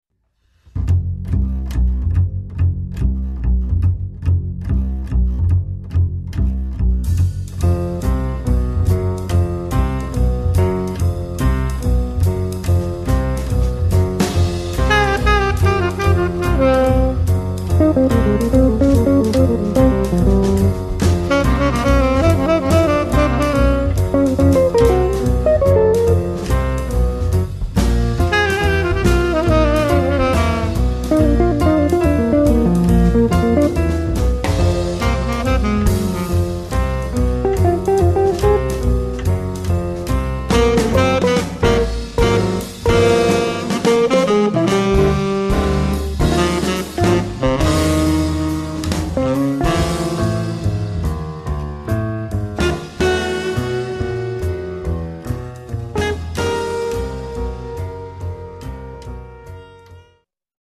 sax tenore
piano
contrabbasso
batteria